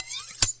厨房 " 抛光厨房刀 2
描述：抛光菜刀（2/4）。
Tag: 厨房 抛光